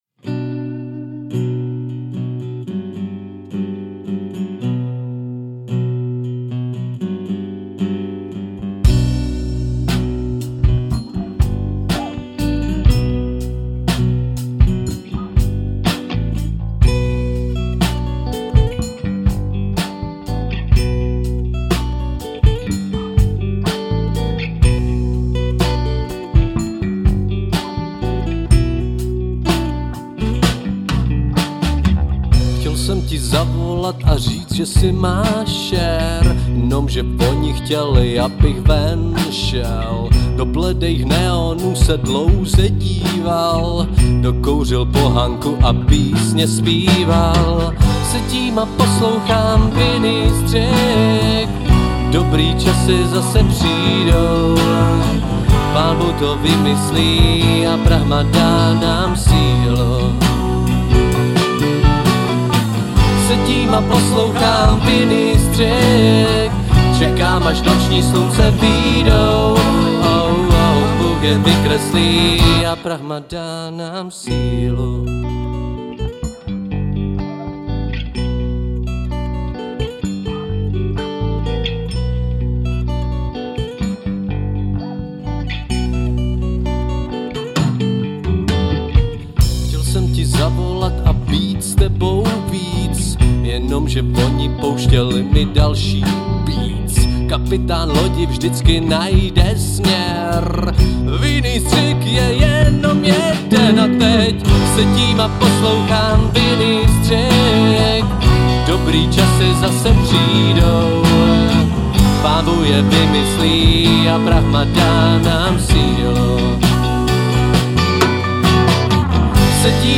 Žánr: Rock
Záznam z živé - studiové - videosession.